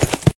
PixelPerfectionCE/assets/minecraft/sounds/mob/horse/gallop4.ogg at mc116
gallop4.ogg